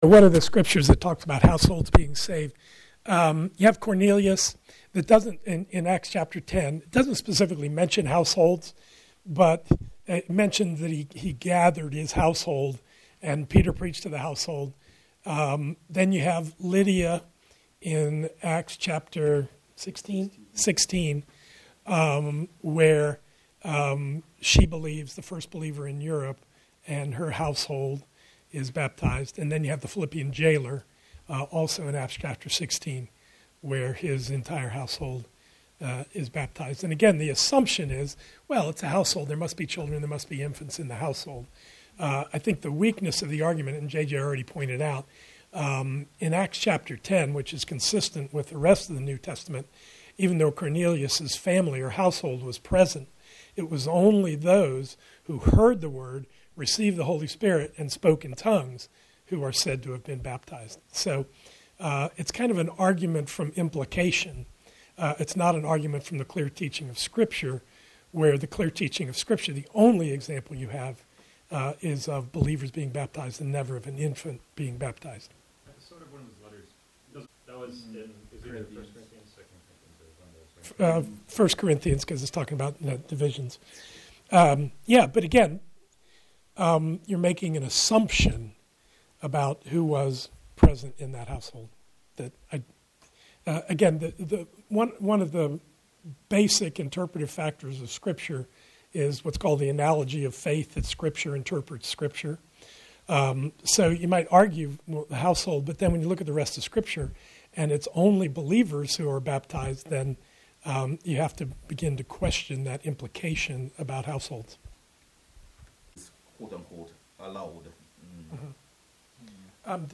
Baptism Class Q&A | Crossway Community Church